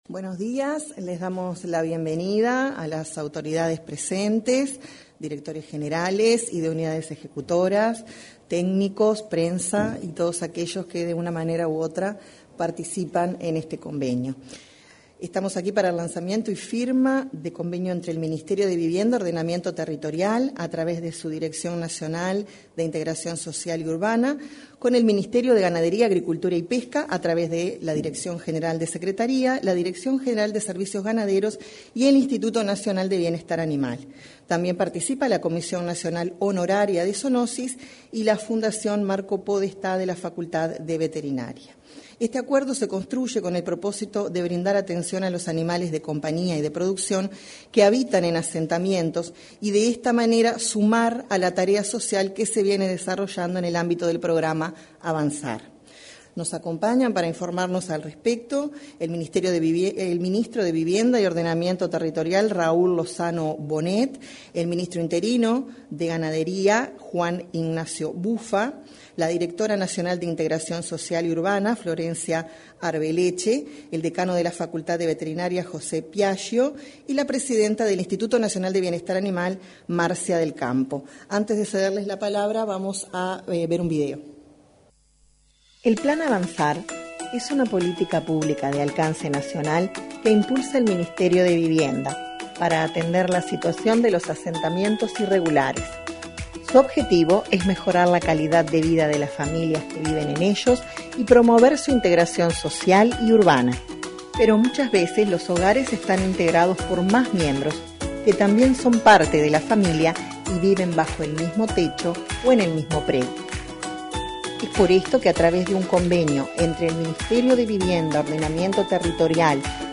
Este lunes 15, en el salón de actos de la Torre Ejecutiva, se suscribió un convenio para la atención de animales de compañía y producción.